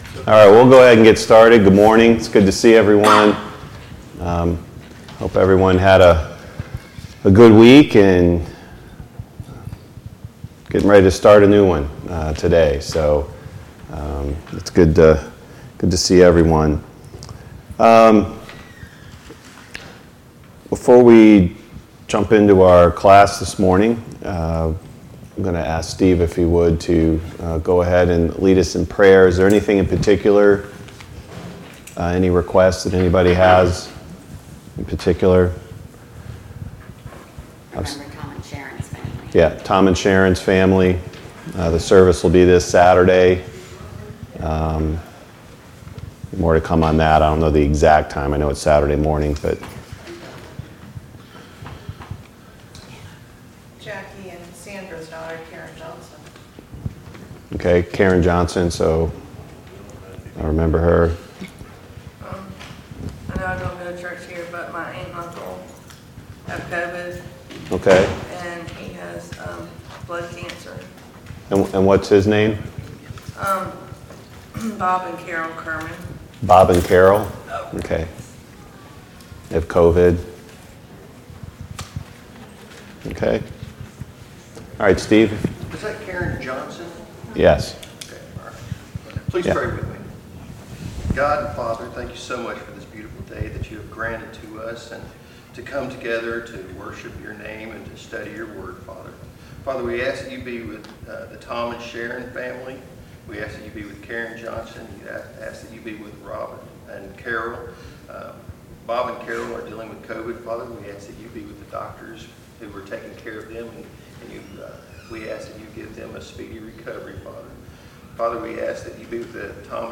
Sunday Morning Bible Class Topics